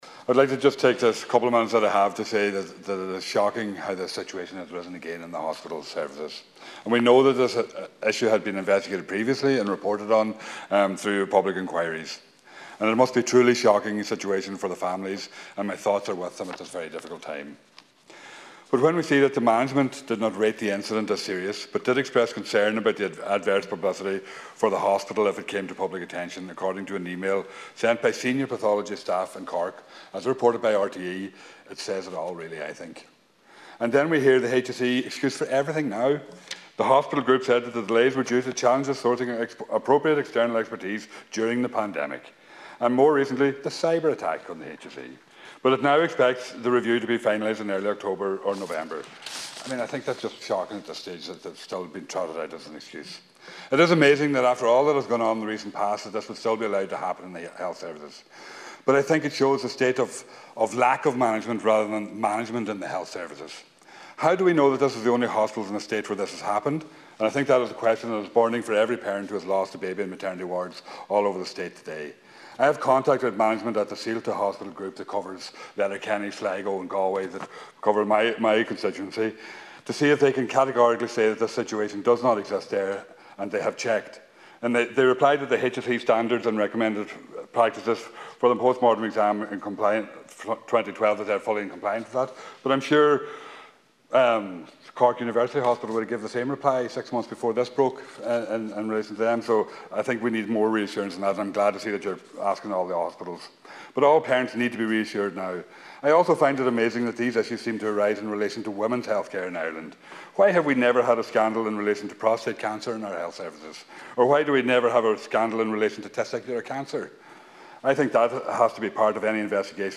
Speaking in the Dail, he says that he has contacted the Saolta University Hospital Group, urging them to categorically state that the situation does not exist there: